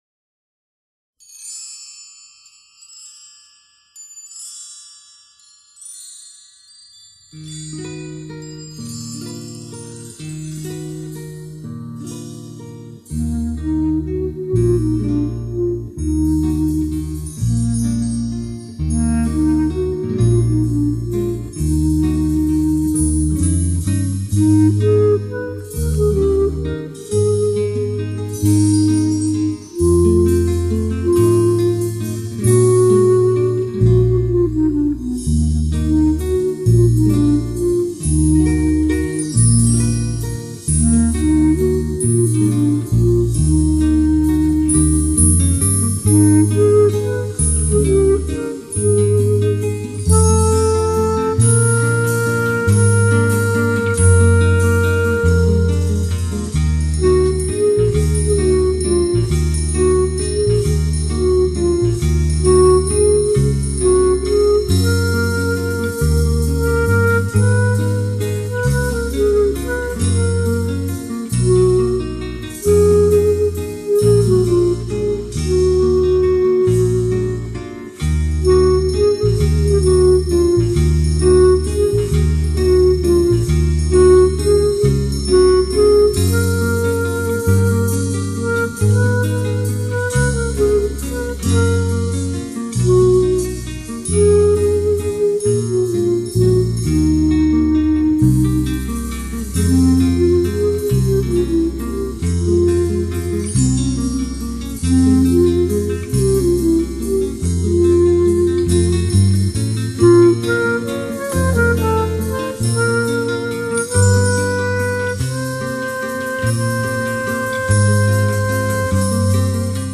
Folk (Jewish) / Instrumental | Artwork included